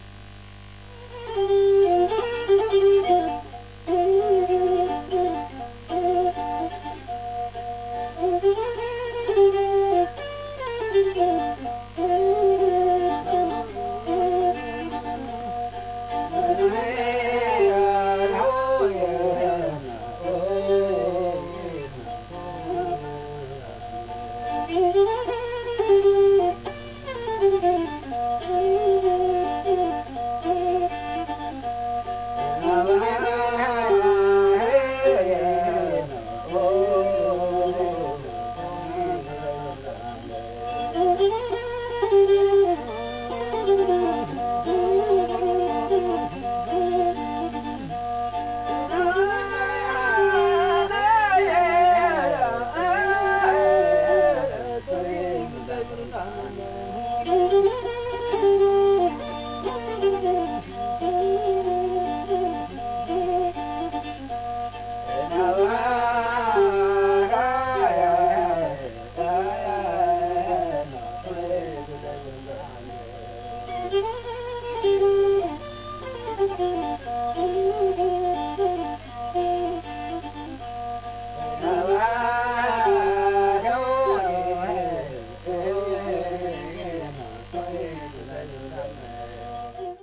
アフリカ、インド洋に浮かぶマダガスカル島にて、2011年～12年にかけて録音した音源集！
※レコードの試聴はノイズが入ります。